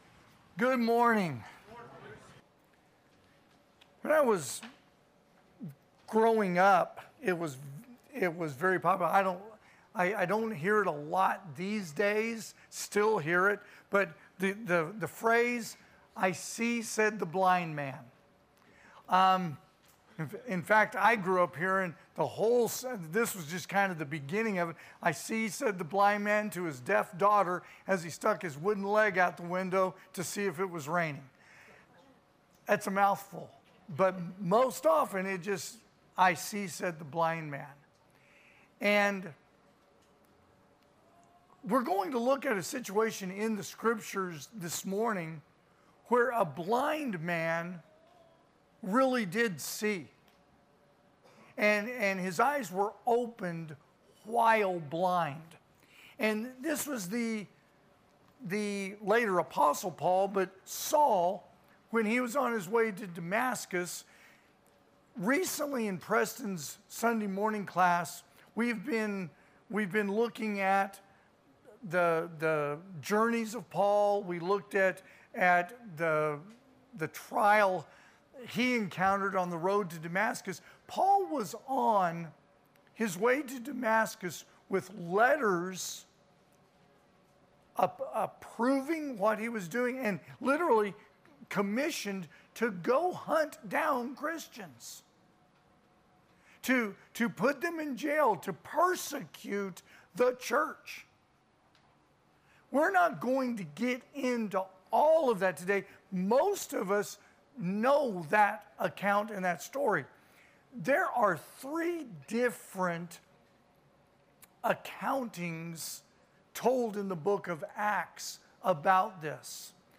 2025 (AM Worship) "I See Said The Blind Man"